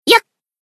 BA_V_Miyako_Battle_Shout_1.ogg